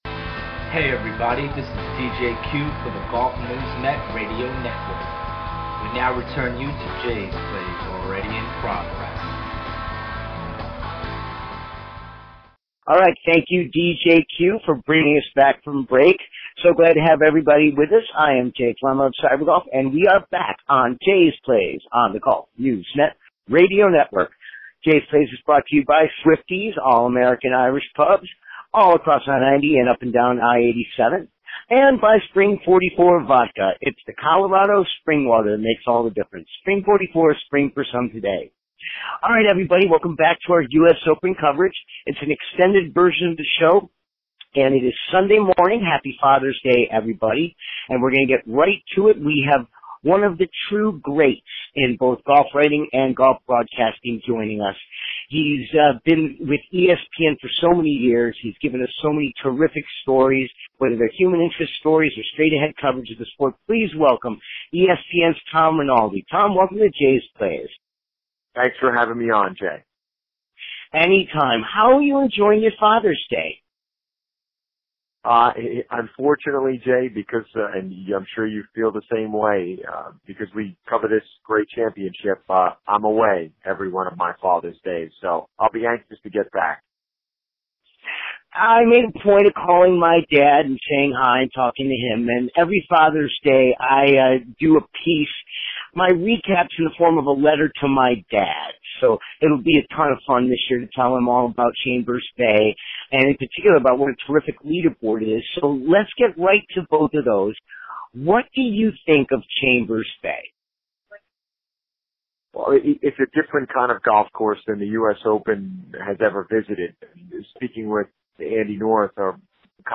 from the U.S. Open at Chambers Bay